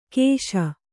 ♪ kēśa